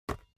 دانلود آهنگ تصادف 48 از افکت صوتی حمل و نقل
دانلود صدای تصادف 48 از ساعد نیوز با لینک مستقیم و کیفیت بالا
جلوه های صوتی